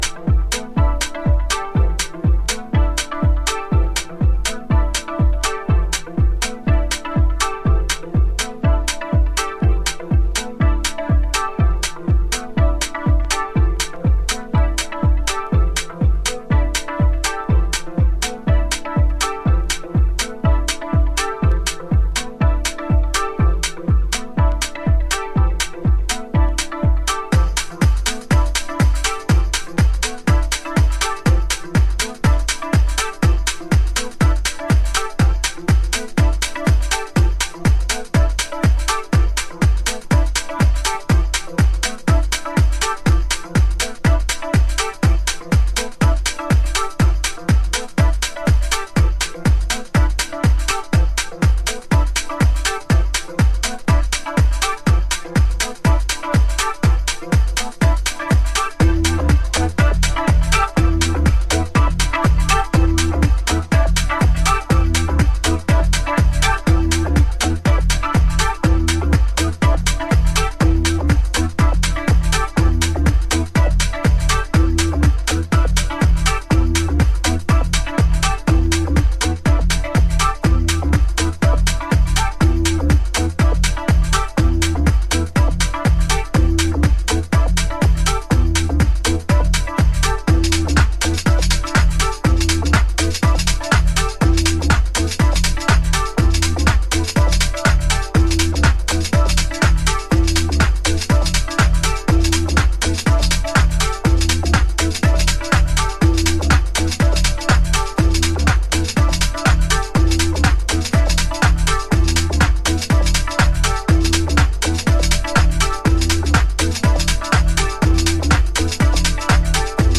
タメの効いたグルーヴでミニマルに展開していきます。使っているベースやシンセが90's 心地。